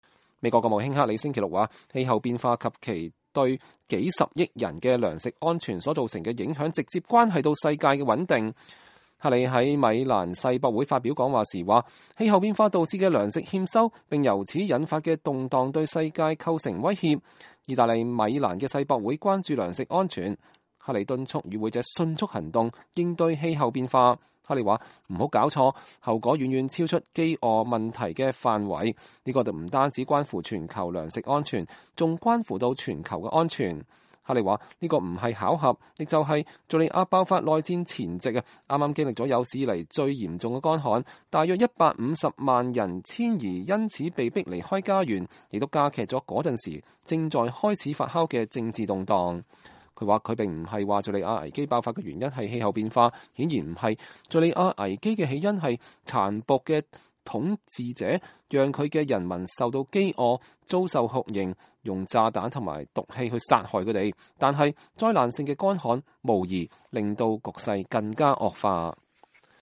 克里在米蘭世博會發表講話